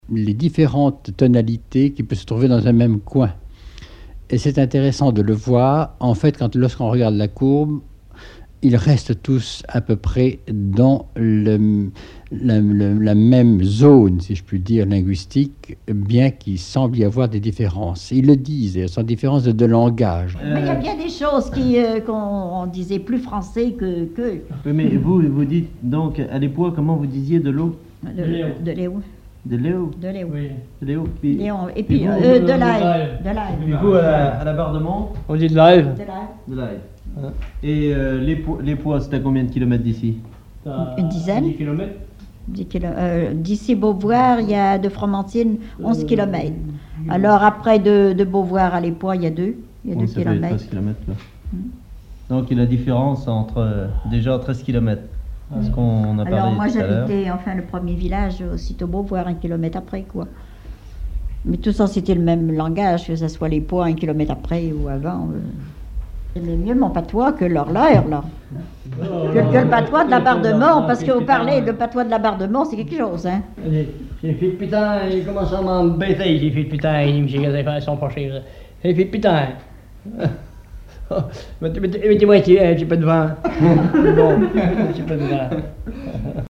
Emisson Départementale, sur Radio France Culture
Catégorie Témoignage